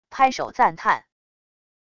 拍手赞叹wav音频